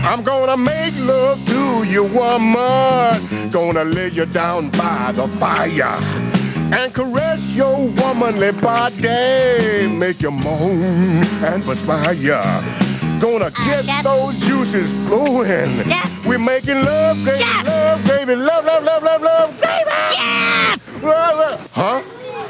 song about making love